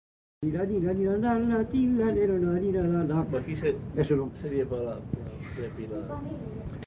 Danses fredonnées
Aire culturelle : Val Varaita
Lieu : Bellino
Genre : chant
Effectif : 1
Type de voix : voix d'homme
Production du son : fredonné